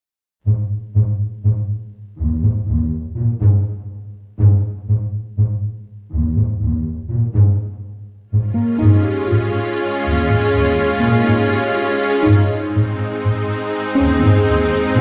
Processional